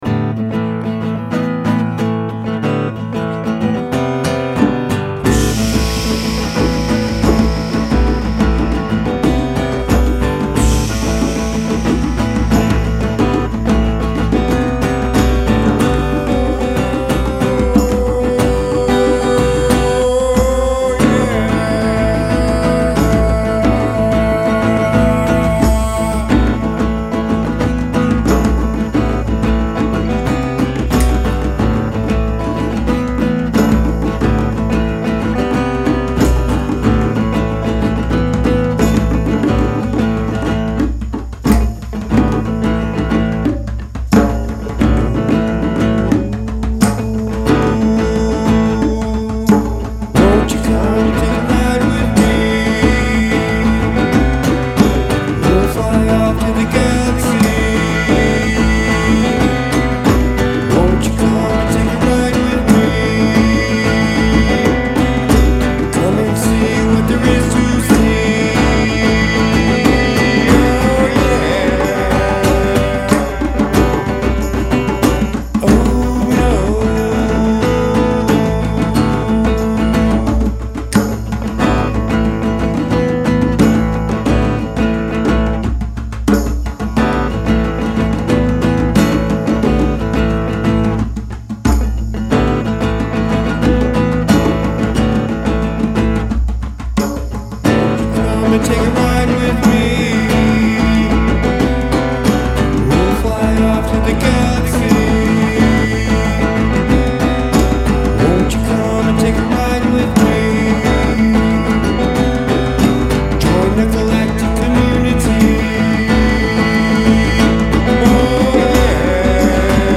2500 mic test Mixdown 1.mp3